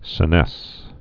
(sə-nĕs)